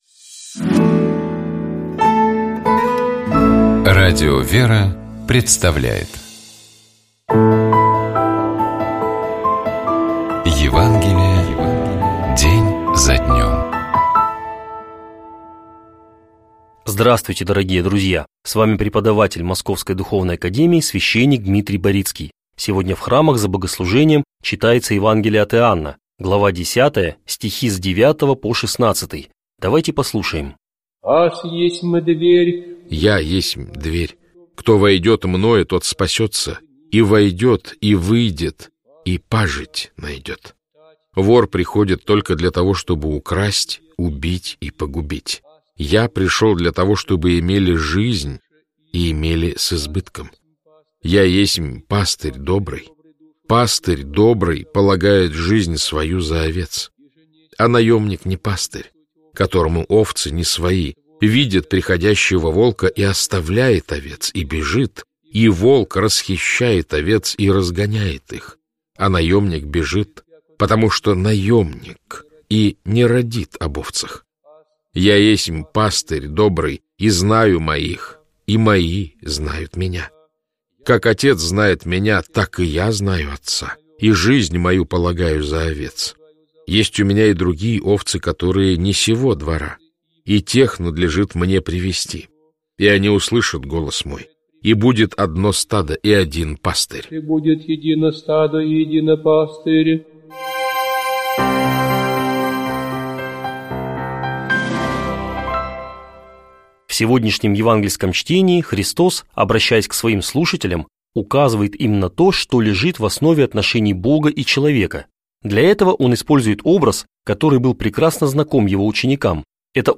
Читает и комментирует священник